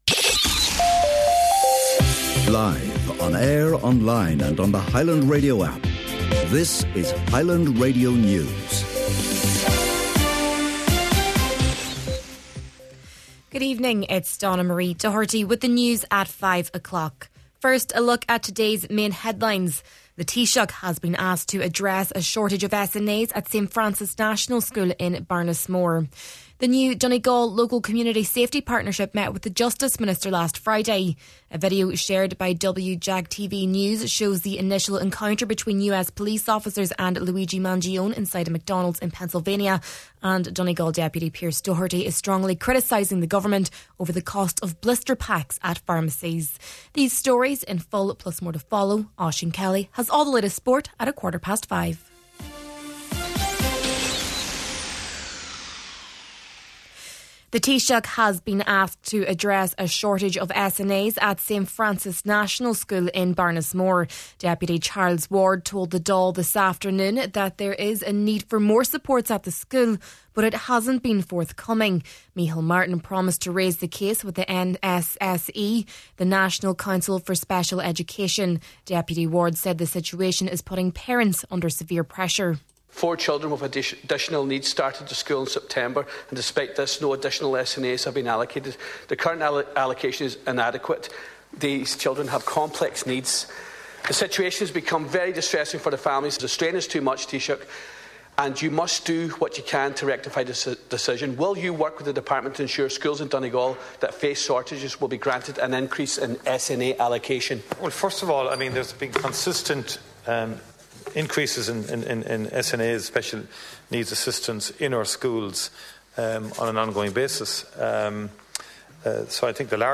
Main Evening News, Sport and Obituary Notices – Wednesday, December 10th